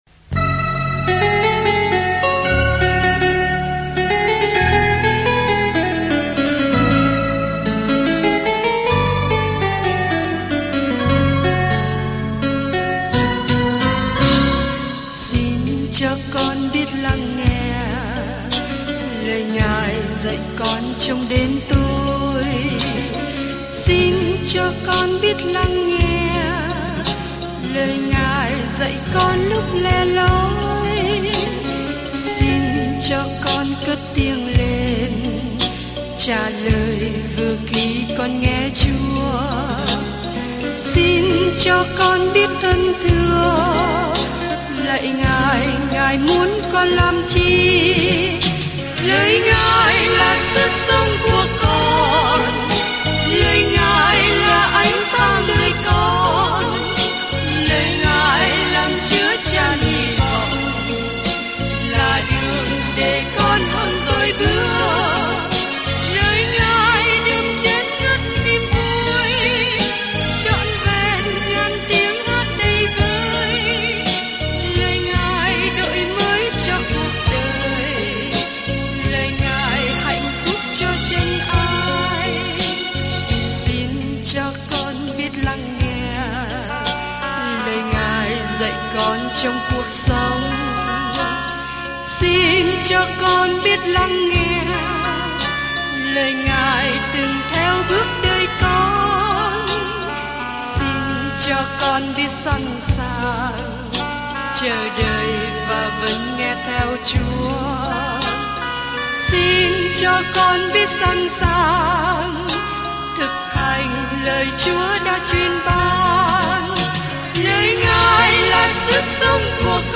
Kinh Thánh Lu-ca 23 Ngày 28 Bắt đầu Kế hoạch này Ngày 30 Thông tin về Kế hoạch Những người chứng kiến loan báo tin mừng Luca kể về câu chuyện Chúa Giêsu từ khi sinh ra cho đến khi chết cho đến khi phục sinh; Luca cũng kể lại những lời dạy của Ngài đã thay đổi thế giới. Du hành hàng ngày qua Luca khi bạn nghe nghiên cứu âm thanh và đọc những câu chọn lọc từ lời Chúa.